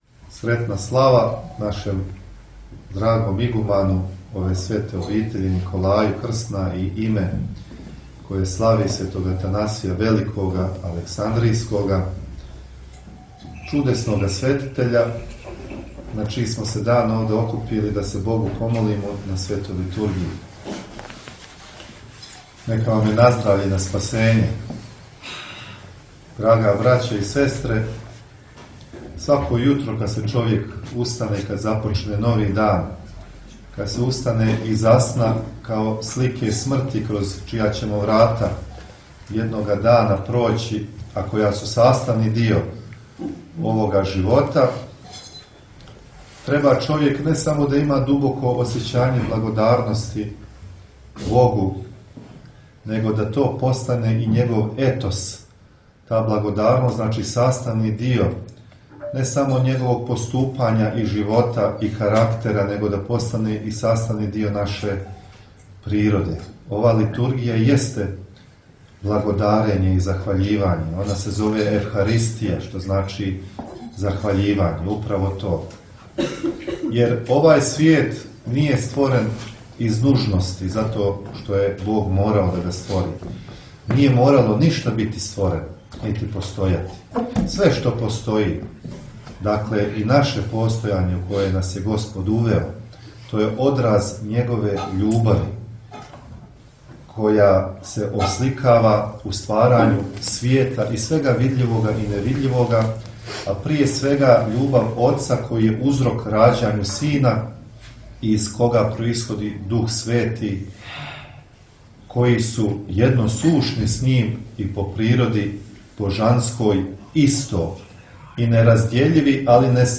Jan 2024 Arhijerejska Liturgija U Manastiru Zlates